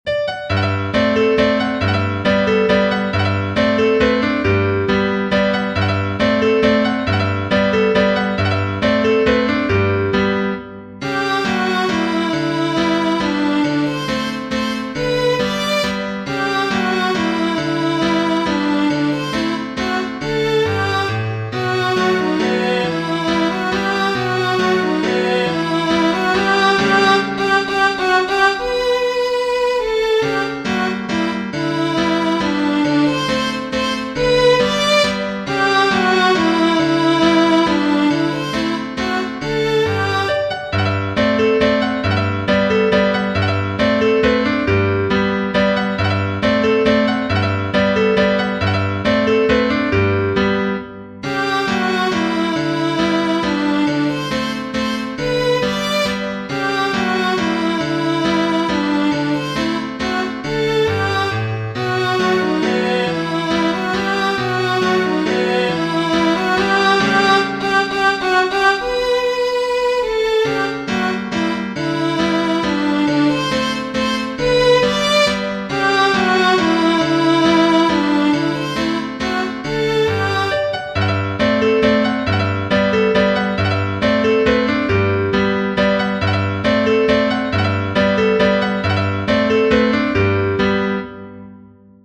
Genere: Romantiche